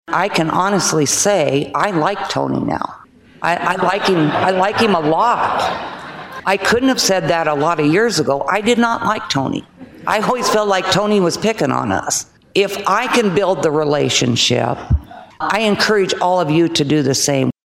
Republican Representative Liz May from Kyle reflected on her evolving relationship with Venhuizen throughout their years of working together in the state Capitol.